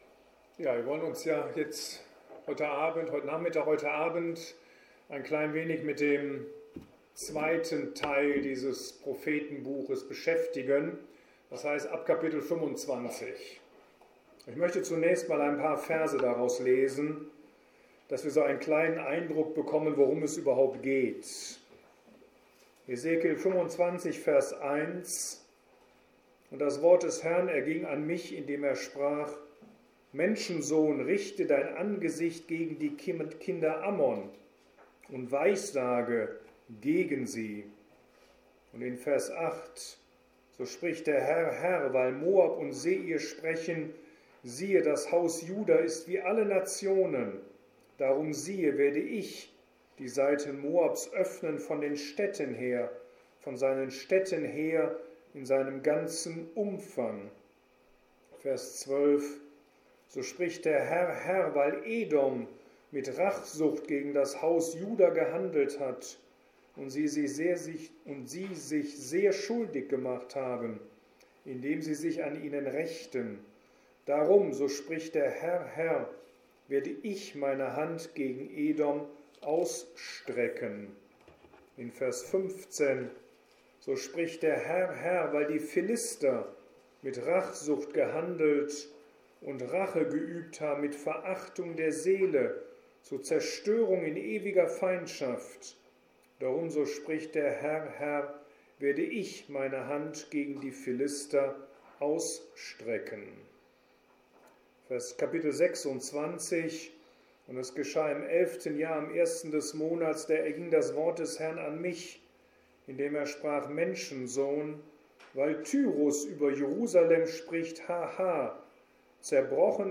Vortrag zum Propheten Hesekiel